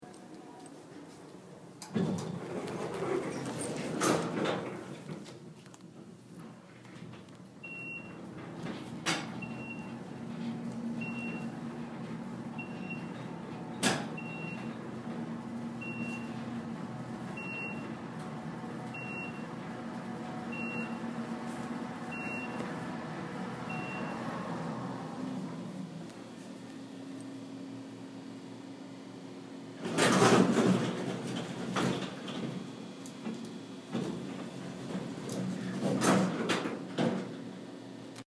Field Recording #3
Location: Vander Poel Hall elevator
Sounds: Elevator doors squeaking, the doors closing, the bell going off on every floor, the elevator speeding up, the elevator banging, the elevator slowing down, the doors opening.